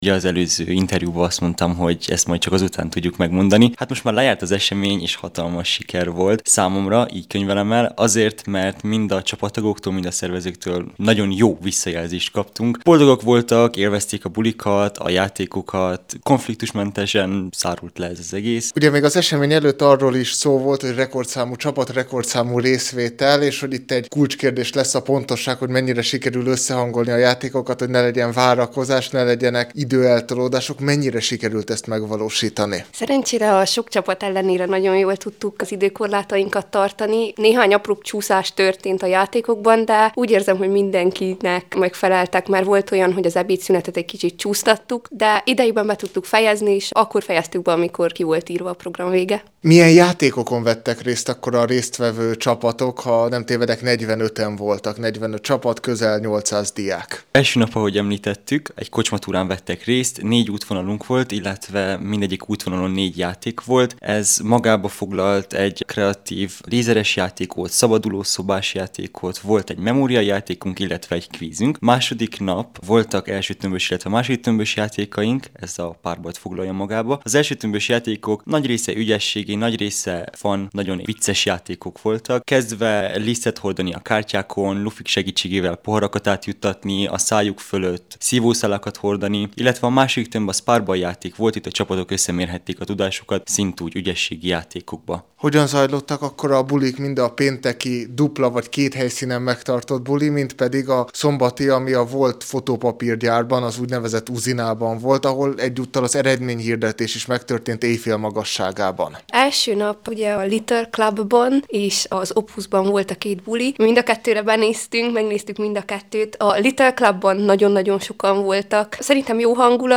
A VII. Febru(t)álisról és az MMDSZ további rendezvényeiről beszélgettünk